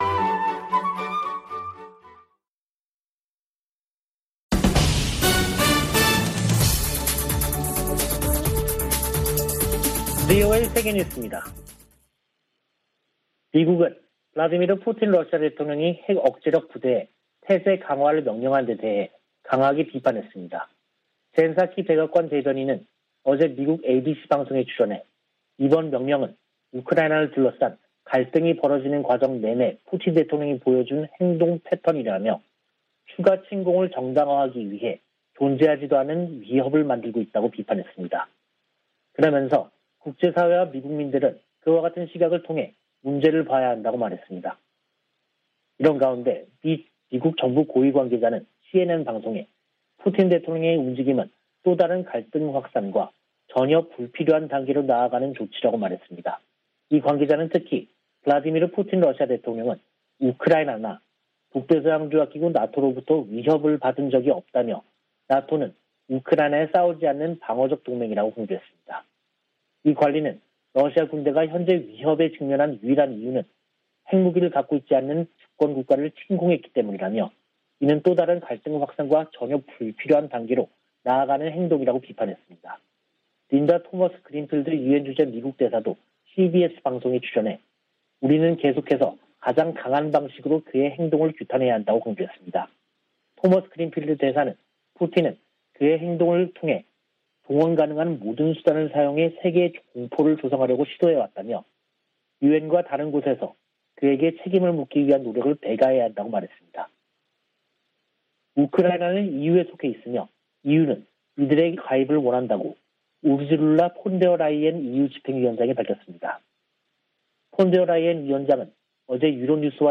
VOA 한국어 간판 뉴스 프로그램 '뉴스 투데이', 2022년 2월 28일 3부 방송입니다. 북한은 27일 발사한 준중거리 탄도미사일이 정찰위성에 쓰일 카메라 성능을 점검하기 위한 것이었다고 밝혔습니다. 미 국무부는 북한의 탄도미사일 시험 발사 재개를 규탄하고 도발 중단을 촉구했습니다. 미한일 외교∙안보 고위 당국자들이 전화협의를 갖고 북한의 행동을 규탄하면서 3국 공조의 중요성을 거듭 강조했습니다.